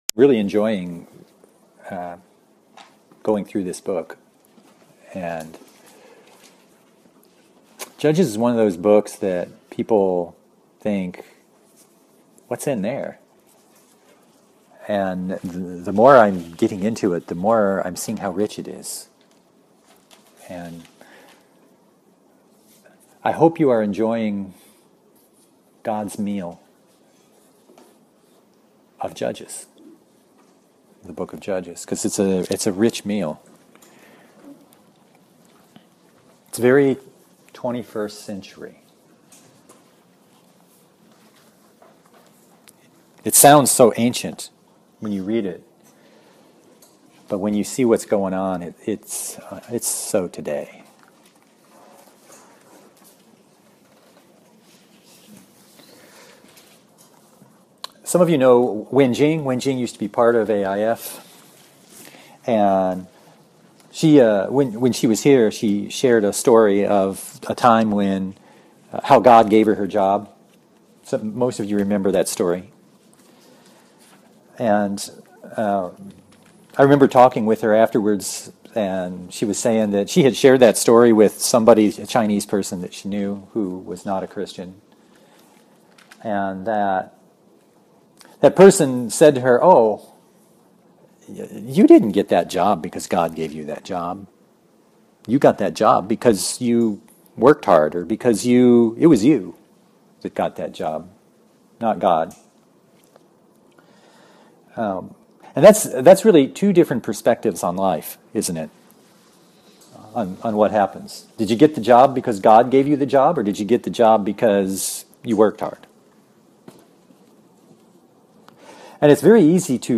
Passage: Judges 16:1-31 Service Type: Sunday